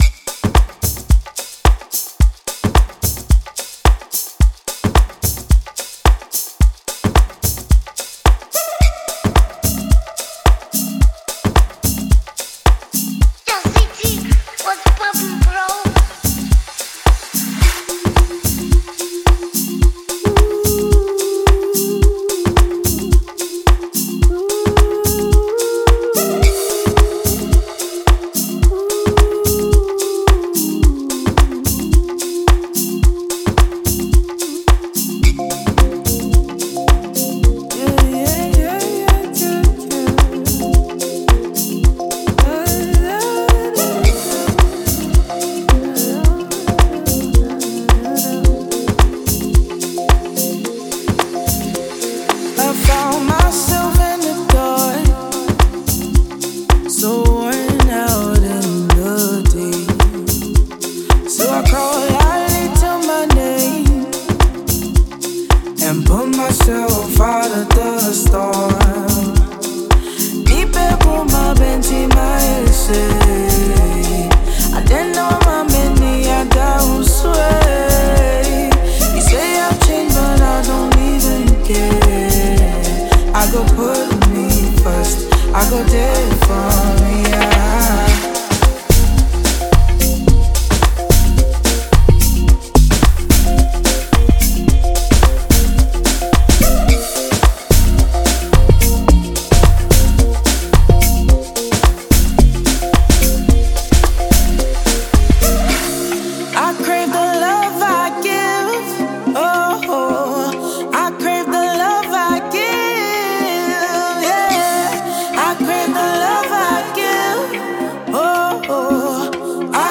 crisp production